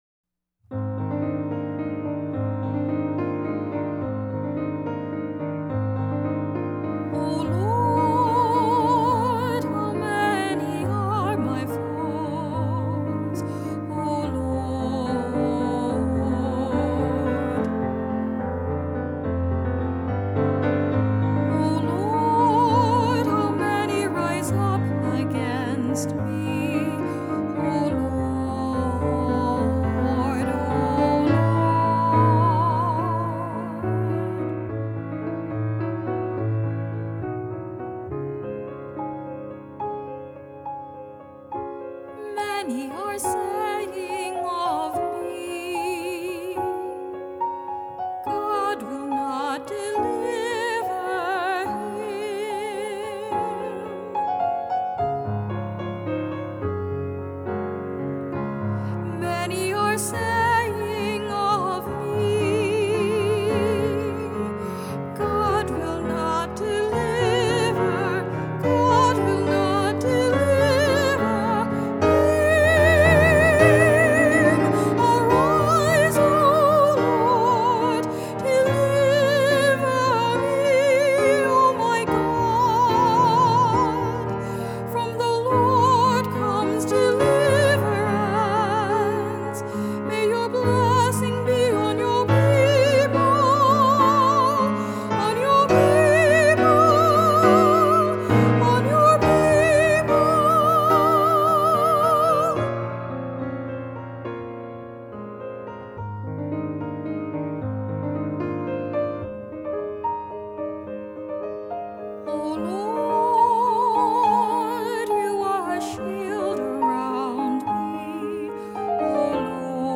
solo vocal music
featuring my Soprano voice